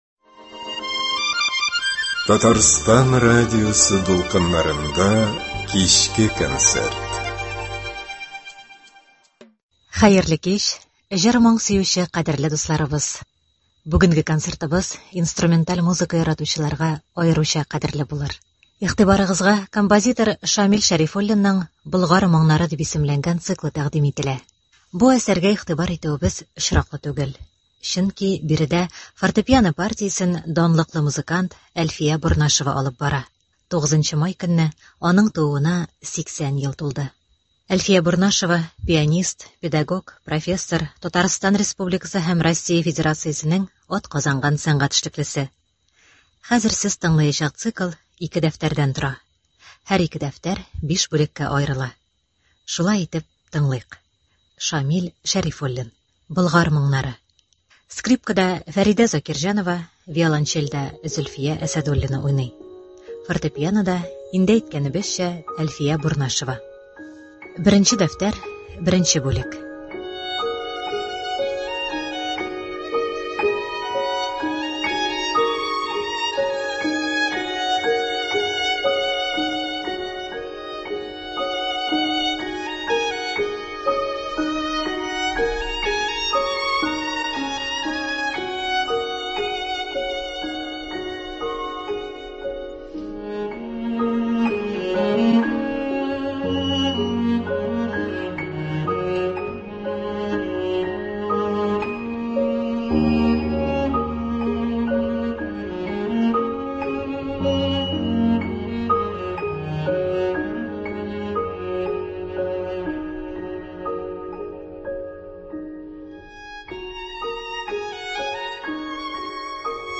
Скрипкада
веолончельдә
Фортепианода